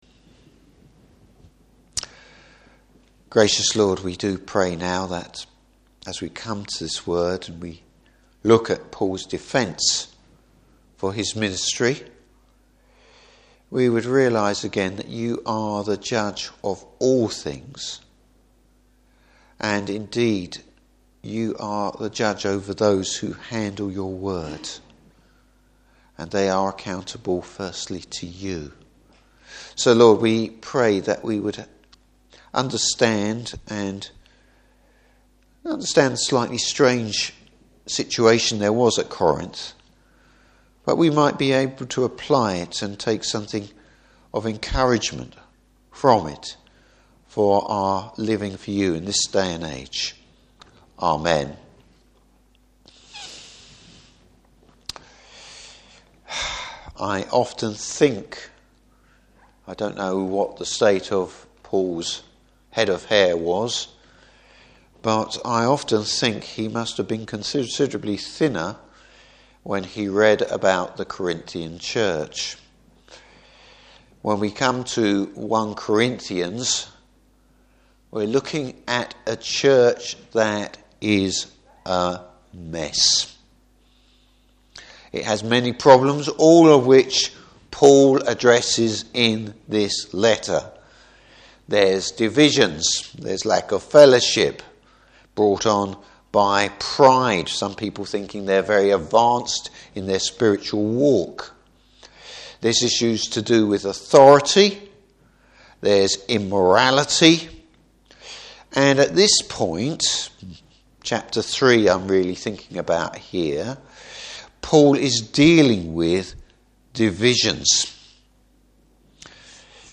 Service Type: Evening Service Paul’s accountability to the Lord.